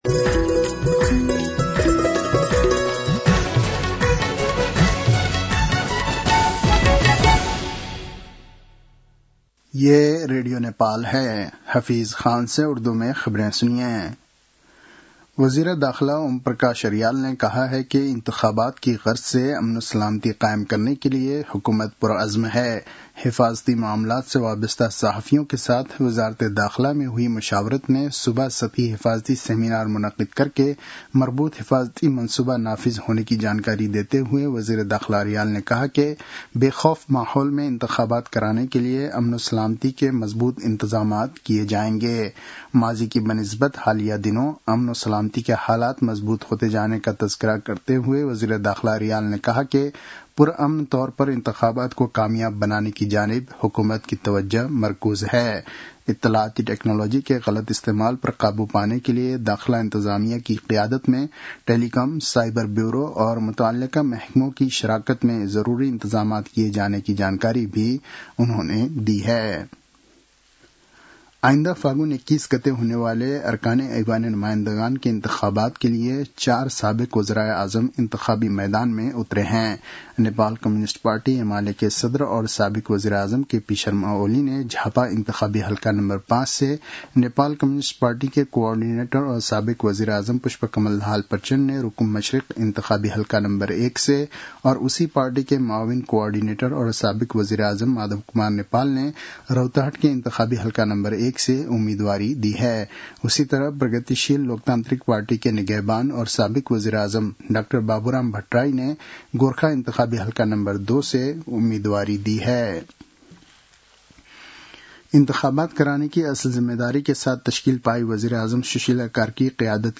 उर्दु भाषामा समाचार : ६ माघ , २०८२